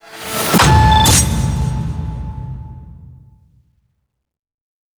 syssd_se_shutter_time.wav